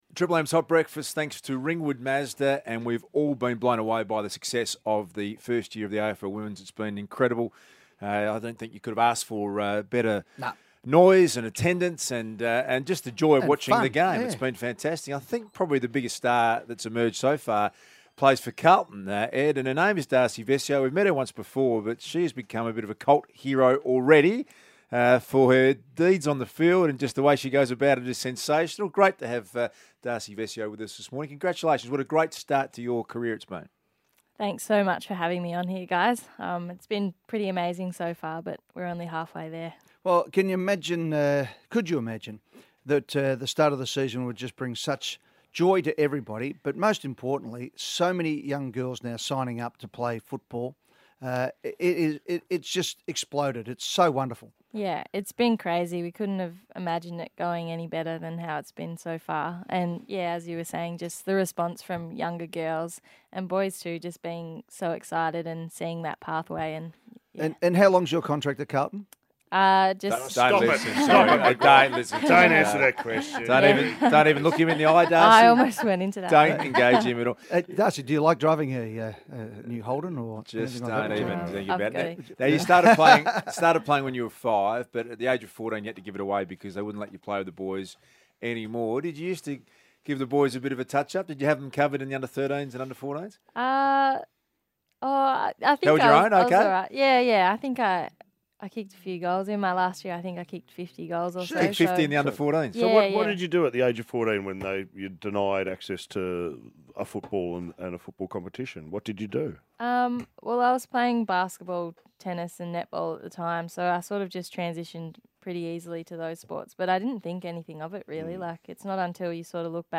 Carlton marquee player Darcy Vescio speaks to Triple M ahead of the Blues' Round 4 AFLW clash against Melbourne.